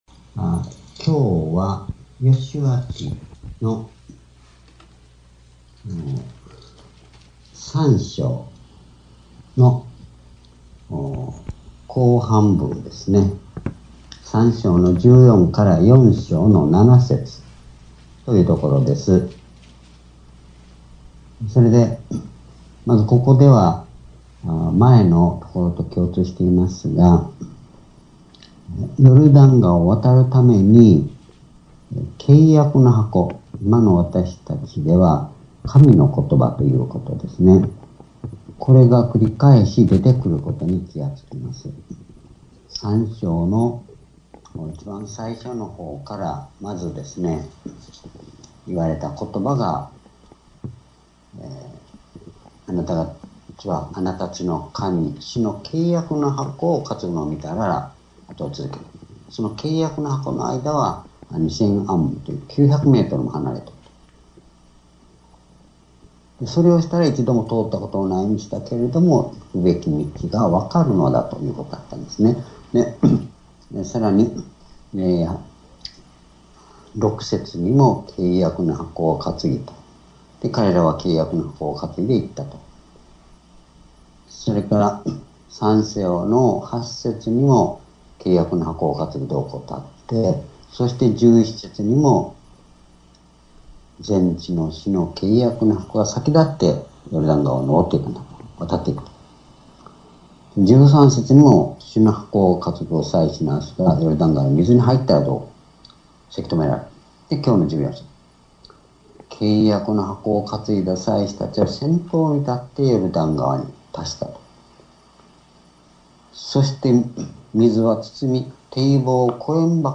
｢｣ヨシュア記3章14節～4章7節 2018年11月6日 夕拝